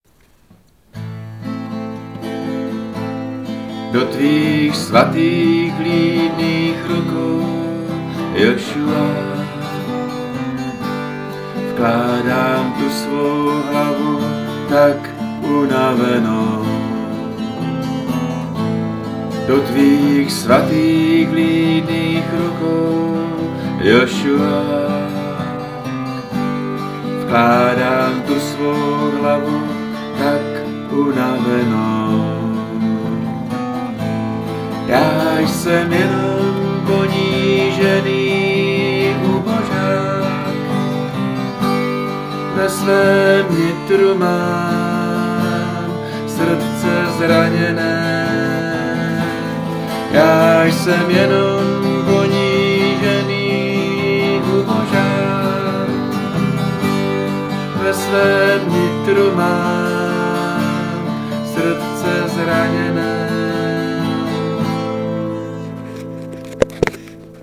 Tak tady jsem udělal vyjímku, je tu demo, opakuje se to proto, aby si to mohl každý zazpívat hned, a kdo by se nudil, tak ať tam vystřihne při tom opakování druhý i třetí hlas :-)